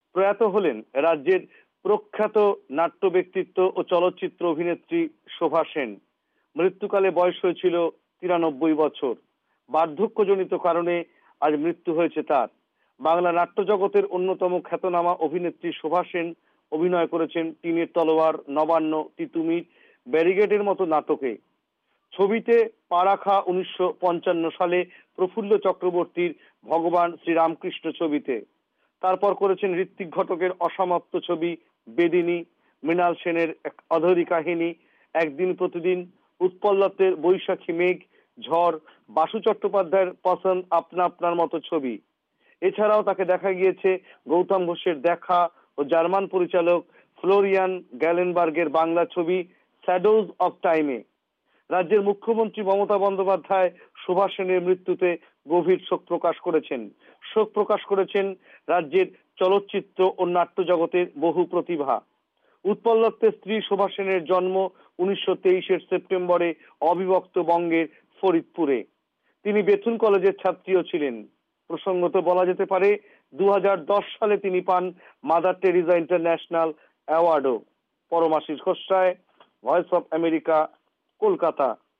কলকাতা থেকে
রিপোর্ট